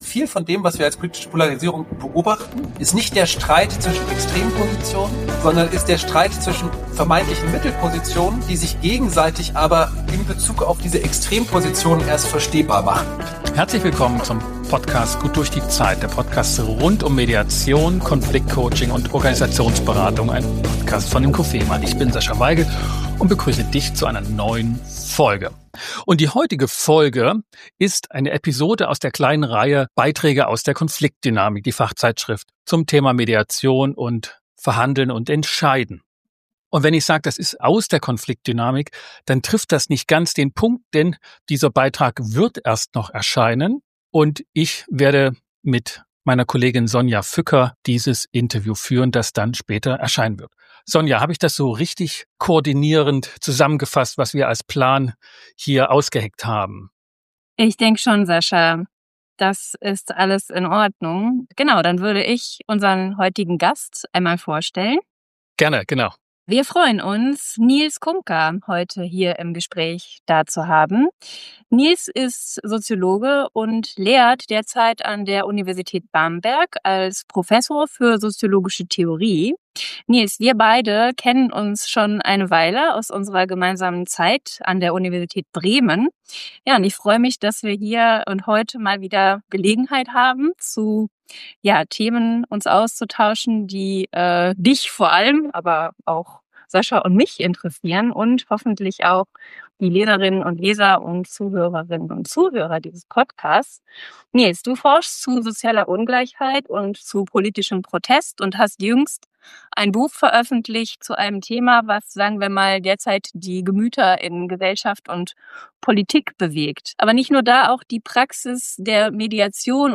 #253 GddZ - Polarisierung der Gesellschaft? Im Gespräch